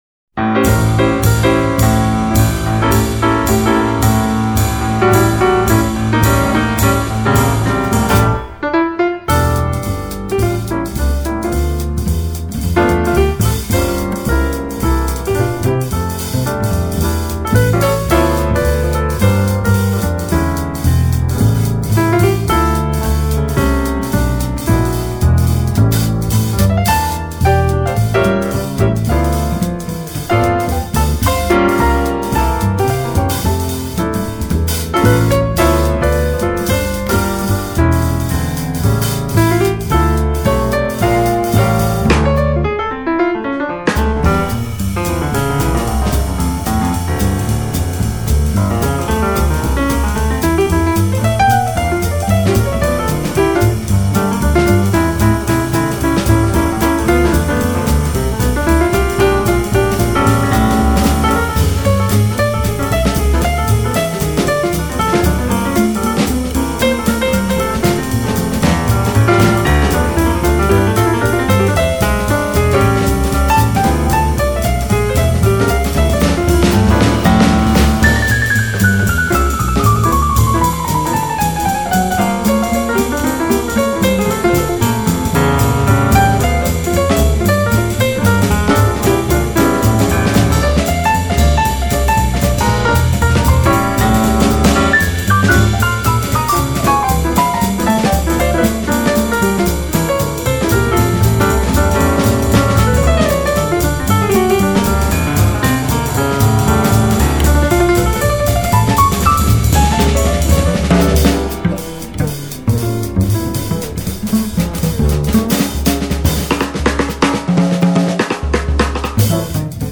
的睿智与优雅，但是他的钢琴很流畅，很细腻， 是温柔而沉静的，你不需要
爵士鋼琴專輯